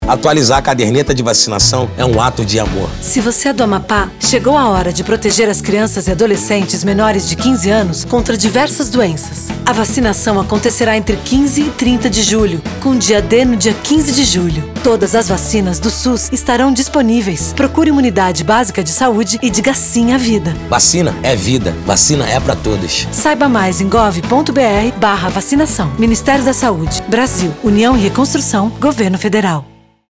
Áudio - Spot 30seg - Campanha de Multivacinação no Amapá - Multivacinação - 1,15mb .mp3 — Ministério da Saúde